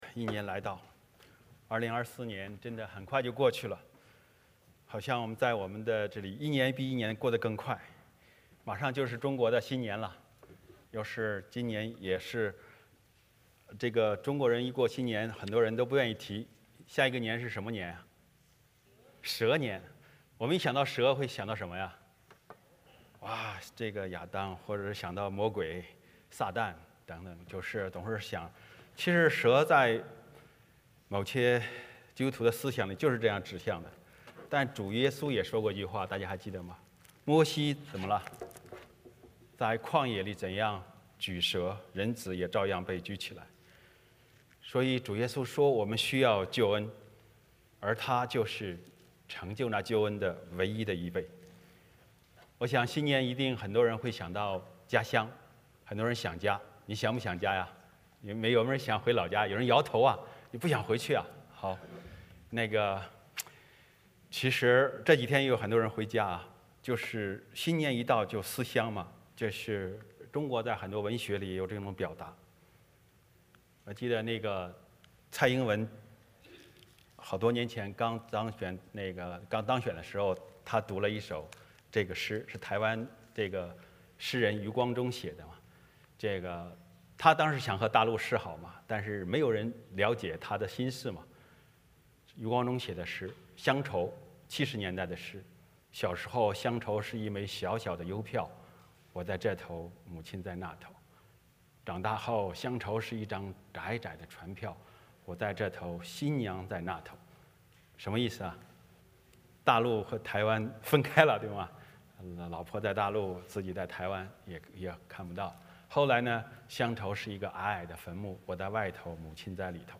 欢迎大家加入我们国语主日崇拜。